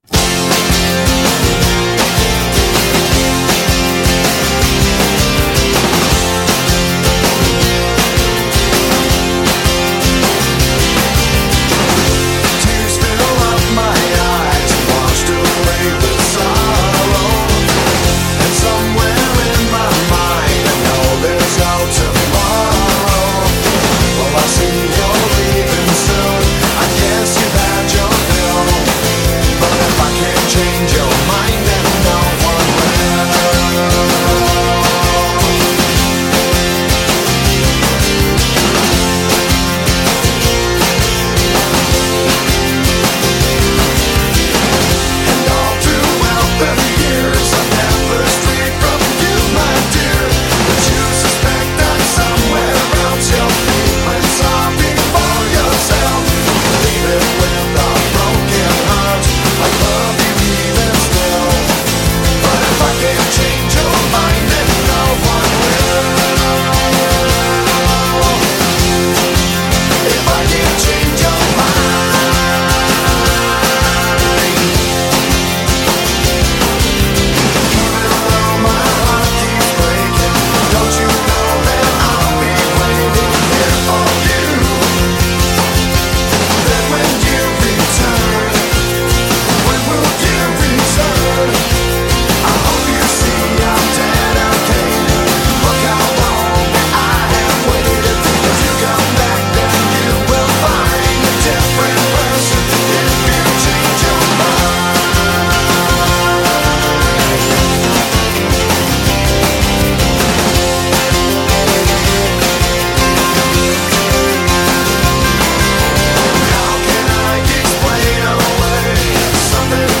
A great album: guitars buzz and catchy melodies abound.